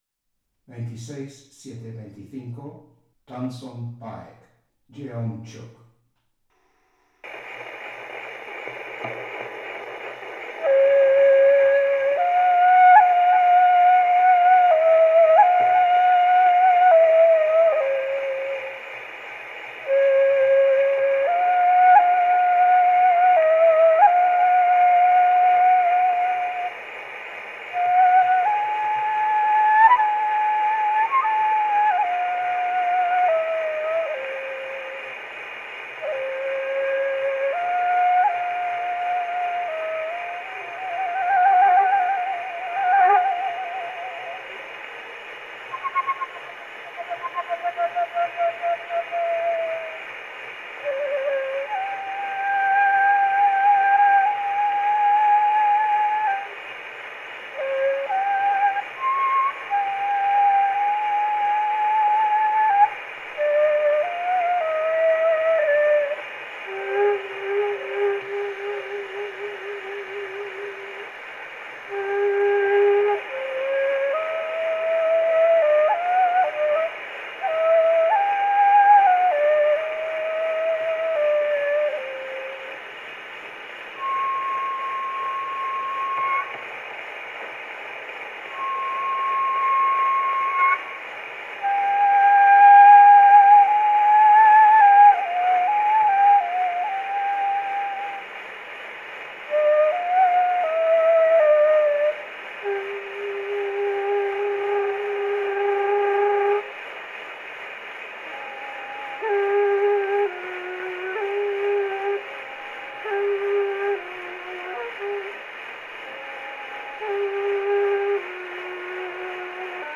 Original sound from the phonographic cylinder:
Recording place: Espazo Cafetería